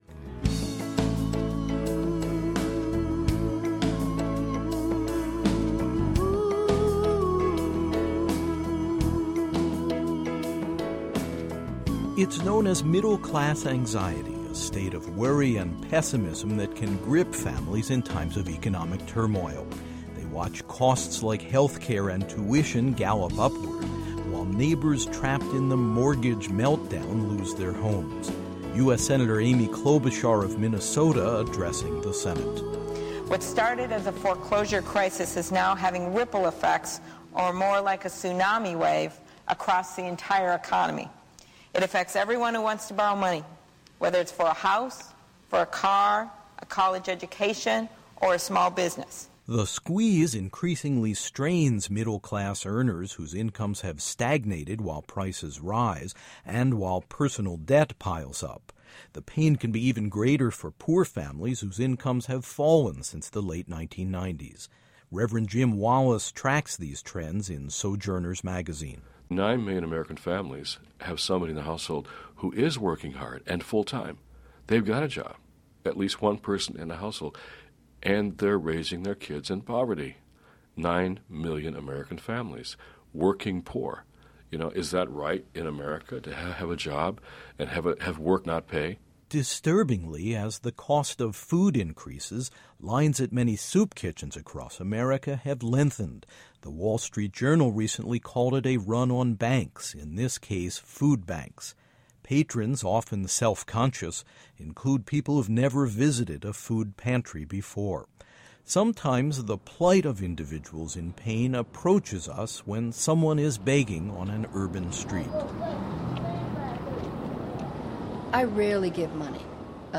This documentary examines why people decide to offer their time and money to answer the need.
In this truly inspiring program, we hear the stories of people who give of their time and financial resources to help members of our society who are struggling — and who often feel marginalized. Why are the givers motivated to help out?